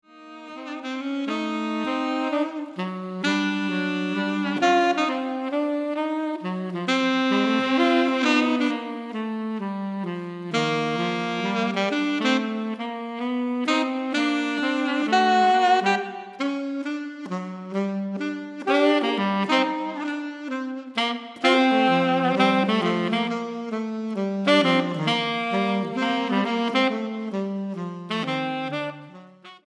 Recueil pour Saxophone - 2 Saxophones